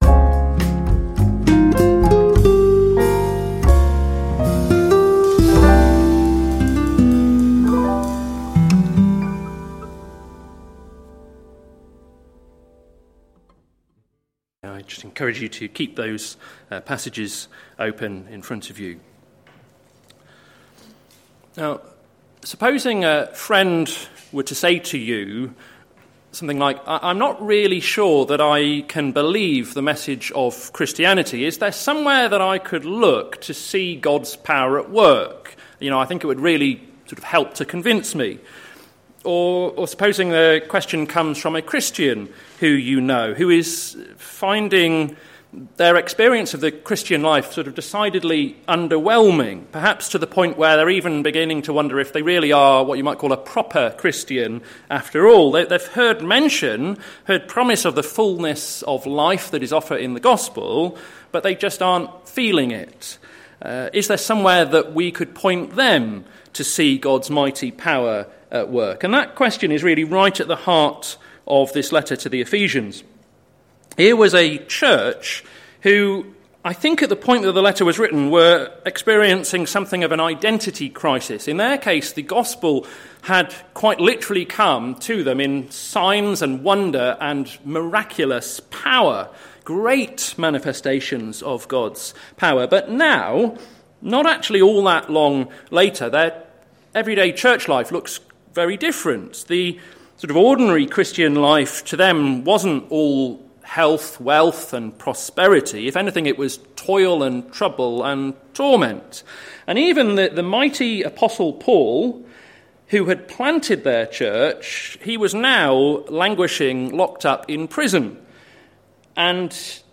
Sermon Series - Glory in the Church - plfc (Pound Lane Free Church, Isleham, Cambridgeshire)